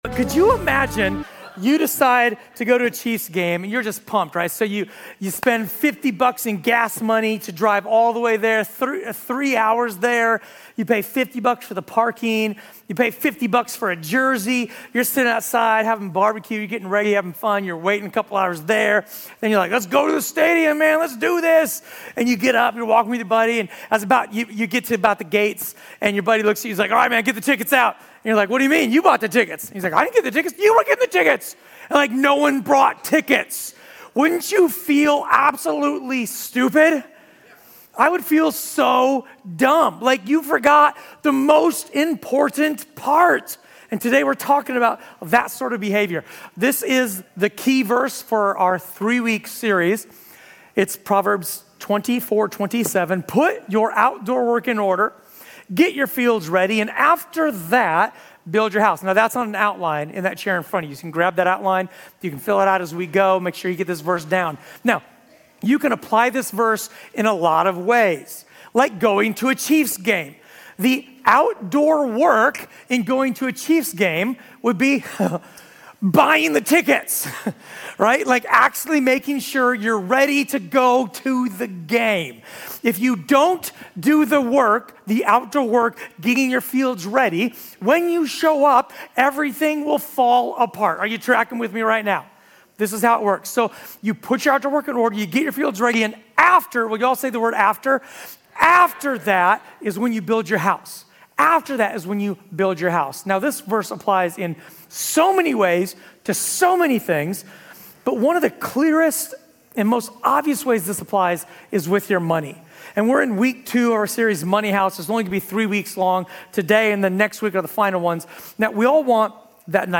A sermon from the series "The Money House."